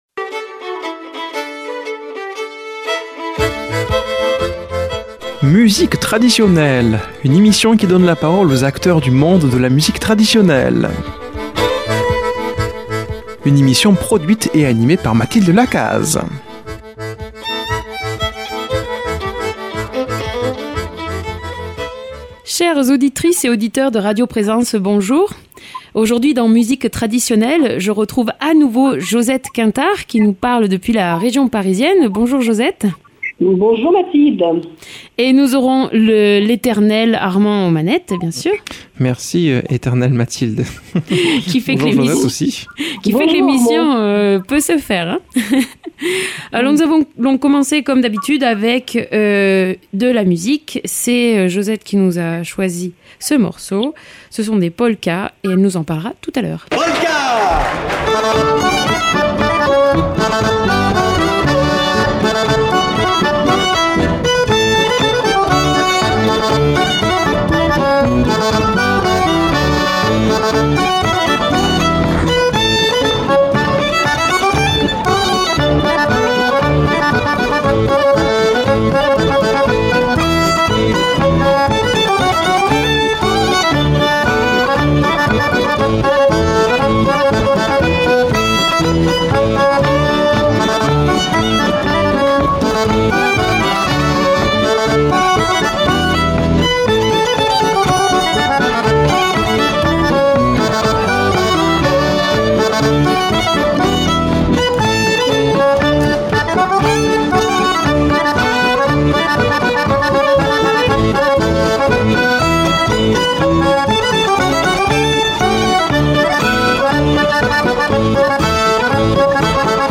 Musique Traditionnelle